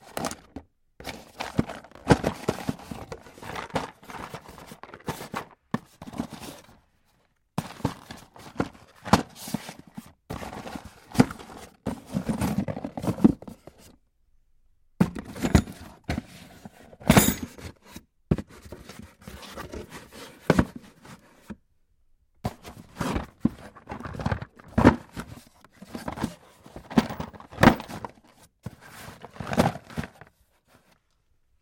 随机的 " 抽屉的木头打开关闭，在垃圾中寻找钥匙的响声1
描述：抽屉木打开关闭搜索通过垃圾密钥rattle1.wav
Tag: 打开 关闭 通过 木材 拨浪鼓 抽屉 搜索键 垃圾